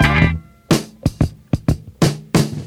• 90 Bpm High Quality Breakbeat D Key.wav
Free breakbeat sample - kick tuned to the D note.
90-bpm-high-quality-breakbeat-d-key-dzy.wav